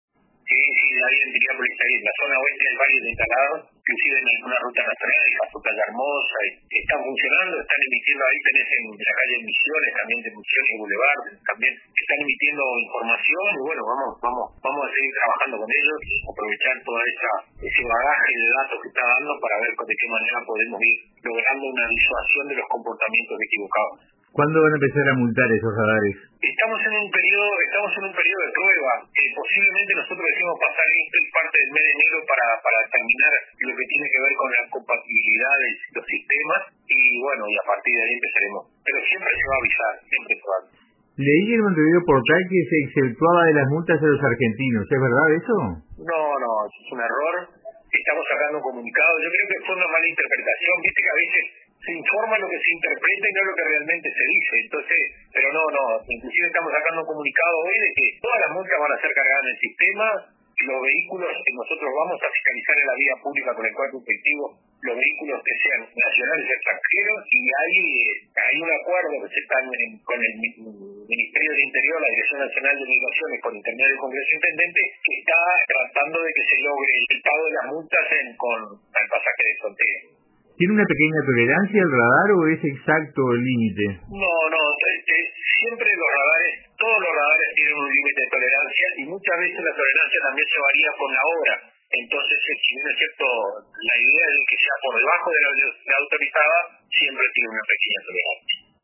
La información difundida fue una mala interpretación, dijo a RADIO RBC el Director de Tránsito y Transporte de la Intendencia de Maldonado, Juan Pígola.